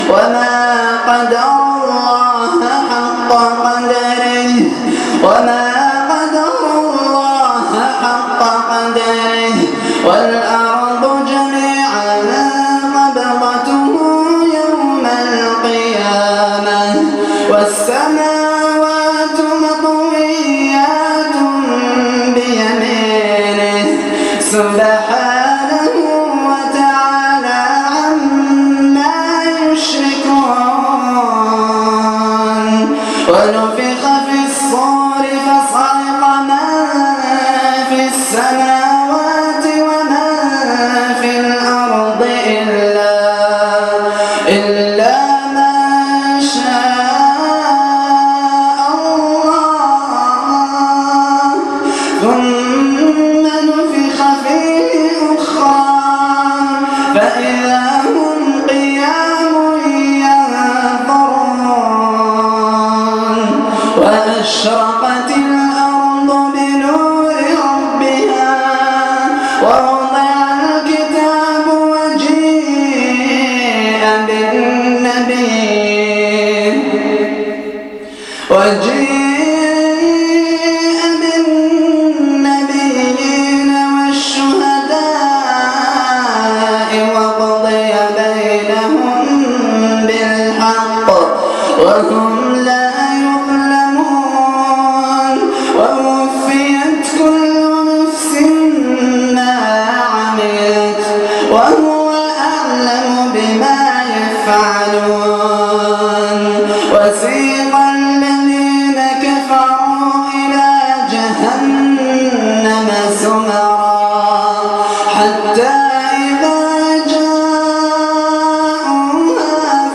تلاوه خاشعه تحيي القلوب باذن الله